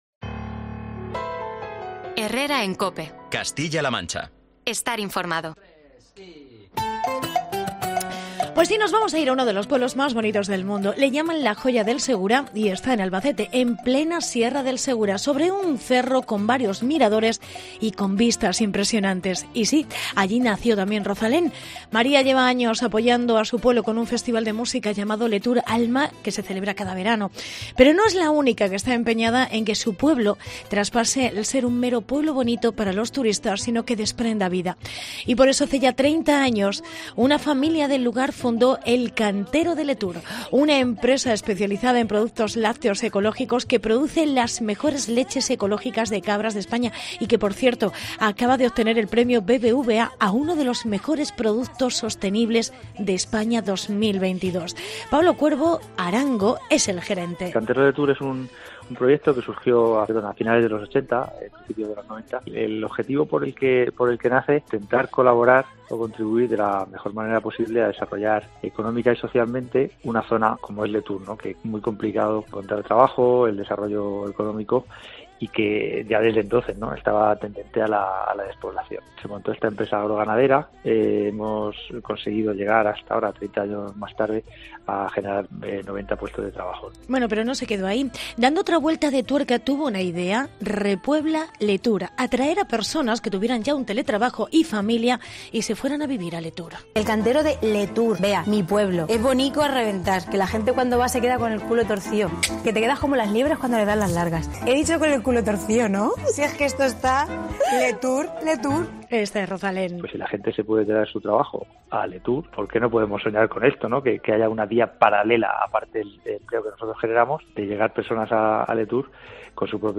Reportaje Repuebla Letur